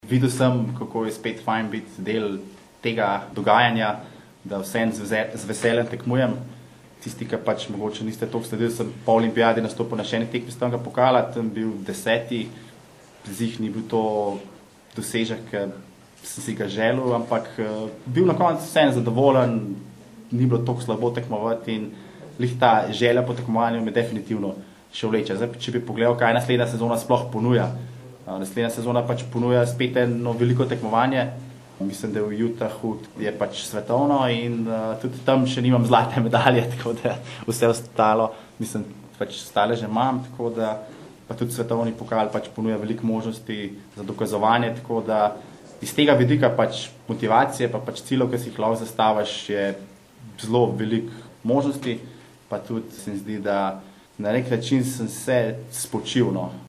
izjava_zankosir3.mp3 (1,4MB)